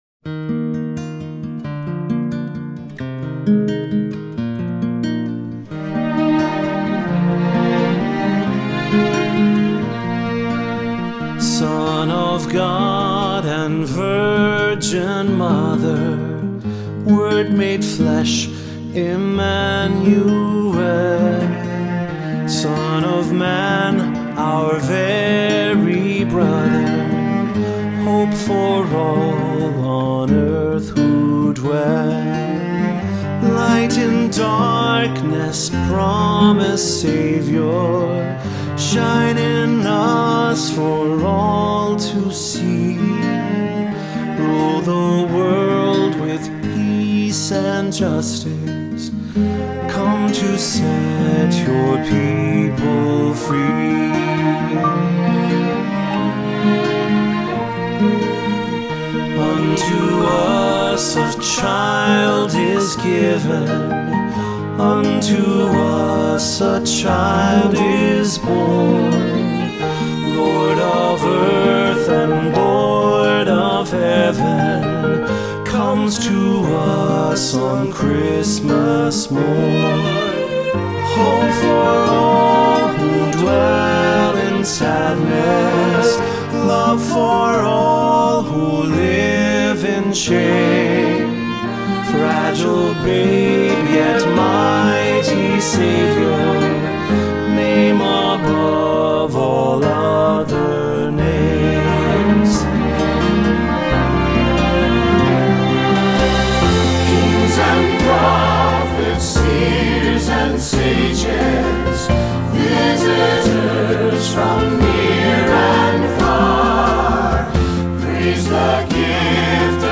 Voicing: Soloist or Soloists,SATB, assembly